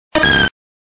Cri de Toudoudou dans Pokémon Diamant et Perle.